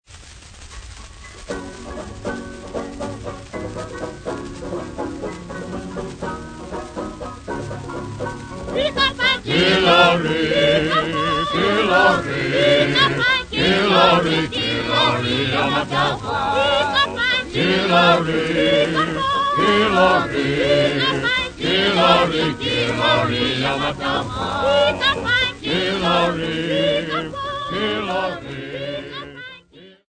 Popular music--Africa
sound recording-musical